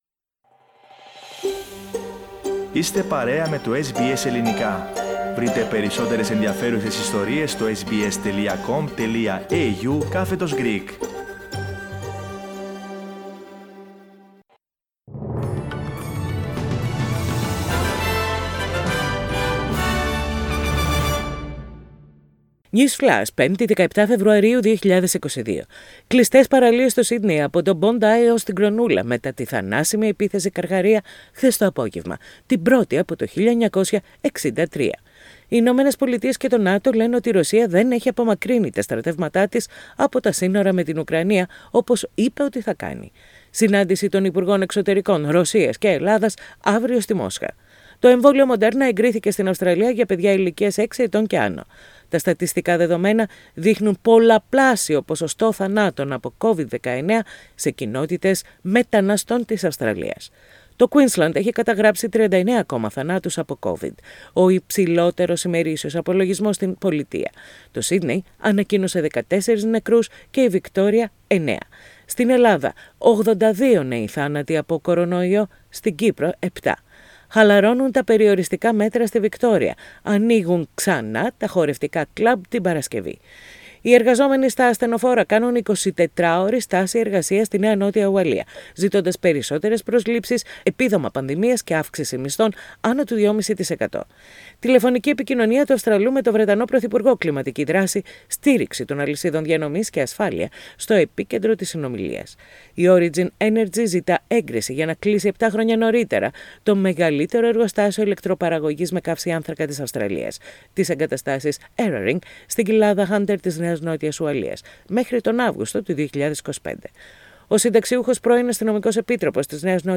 News in Greek.